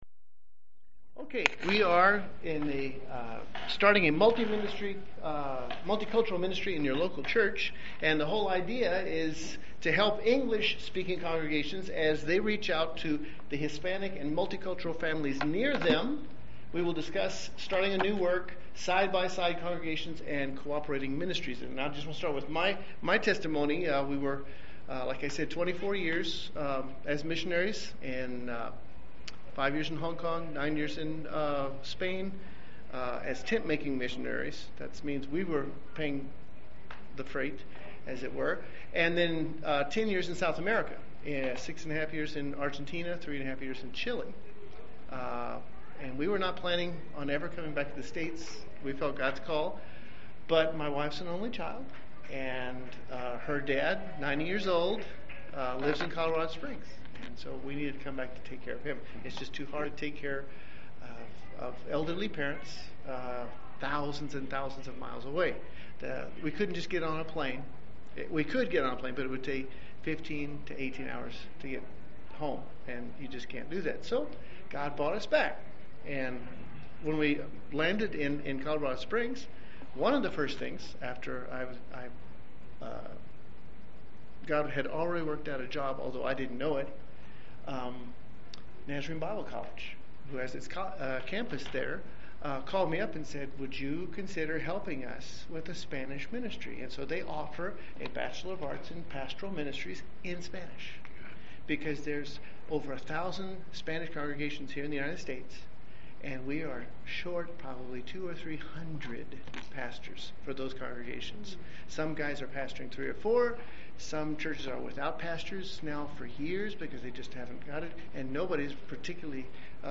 This workshop is intended to help English-speaking congregations as they reach out to the Hispanic and multicultural families near them. We will discuss starting a new work, side-by-side congregations, and cooperating ministries.